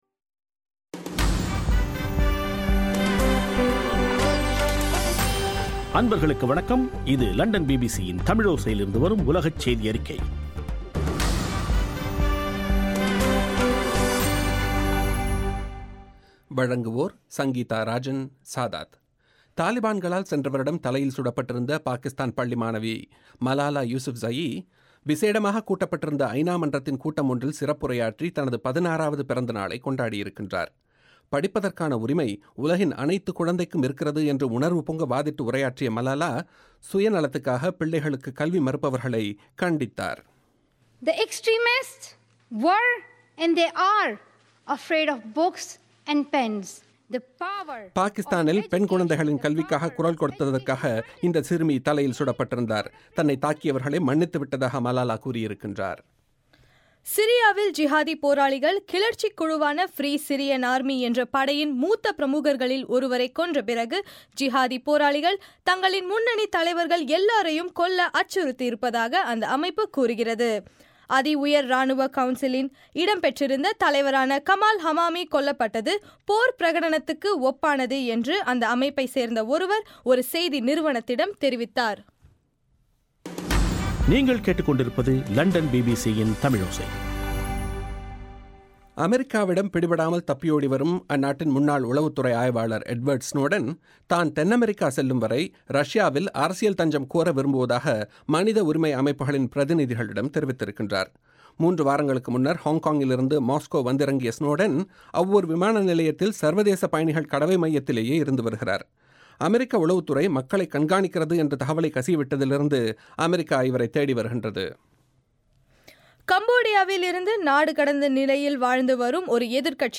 பிபிசி தமிழோசை இலங்கையின் சக்தி எஃப்.எம். பண்பலையில் ஒலிபரப்பிய உலகச் செய்தியறிக்கை